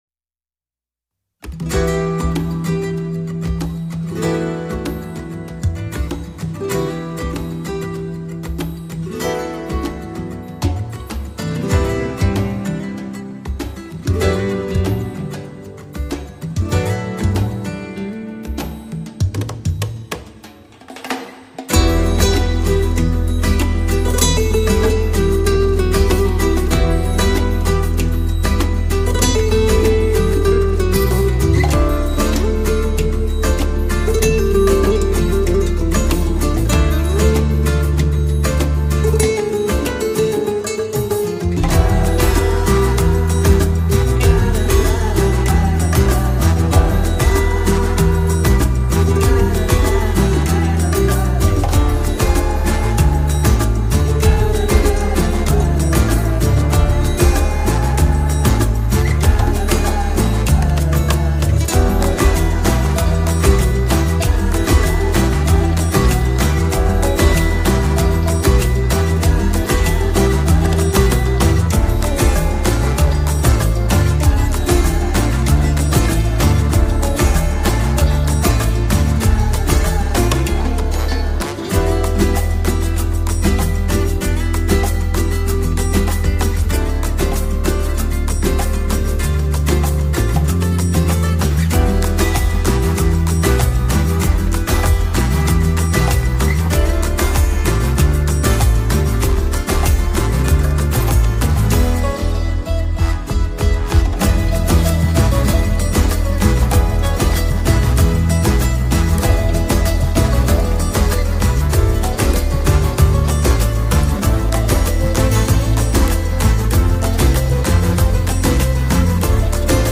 tema dizi müziği, mutlu eğlenceli rahatlatıcı fon müziği.